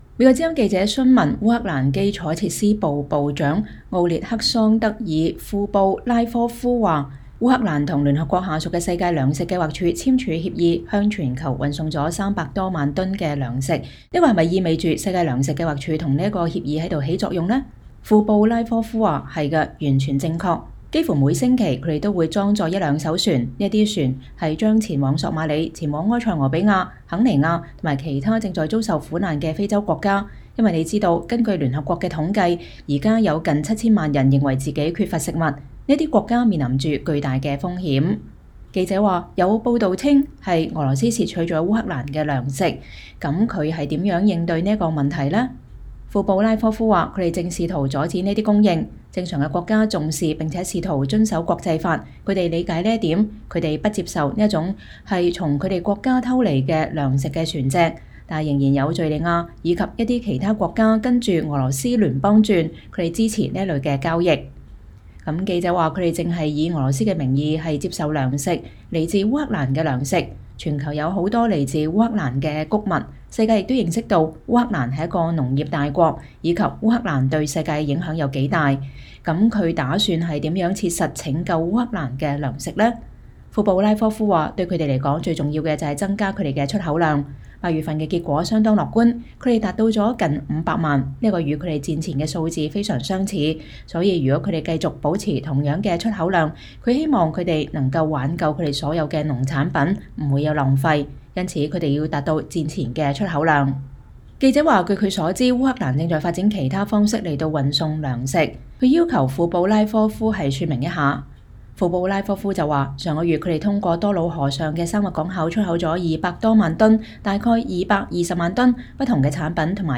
烏克蘭伊爾平 —
美國之音採訪了烏克蘭基礎設施部長奧列克桑德爾·庫布拉科夫(Oleksandr Kubrakov)，討論了聯合國向世界運送烏克蘭糧食的計劃以及烏克蘭修復在俄羅斯入侵期間受損的橋樑所做的努力。